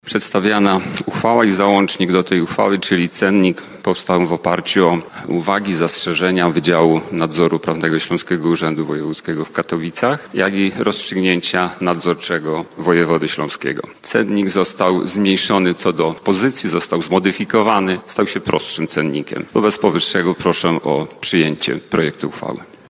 Stosowną uchwałę przyjęli bielscy radni podczas ostatniej sesji Rady Miejskiej.